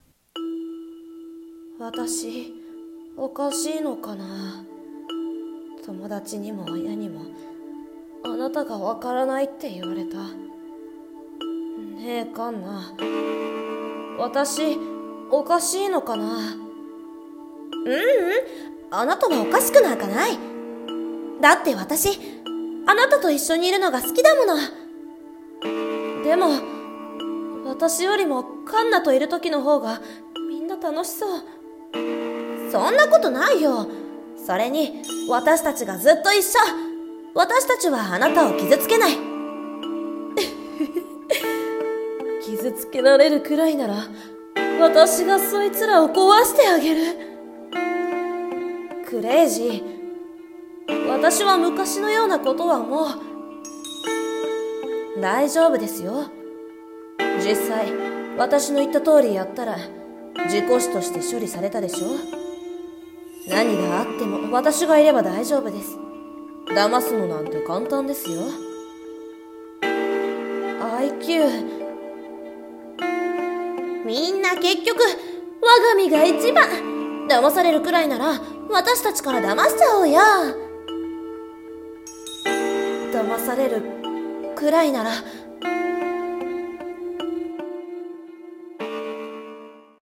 【声劇】騙されるくらいなら